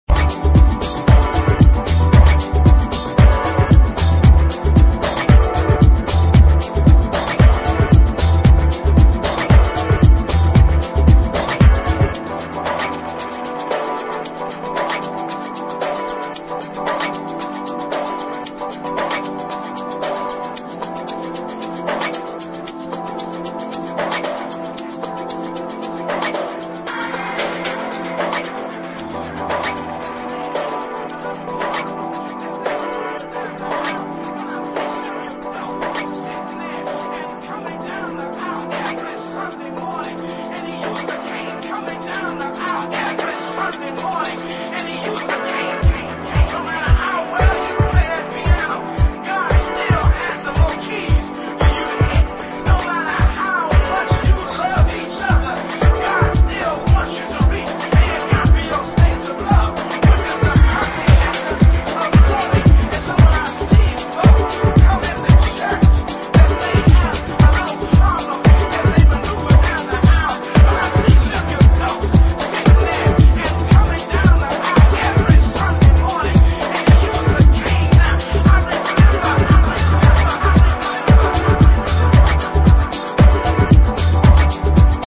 4 deep house cuts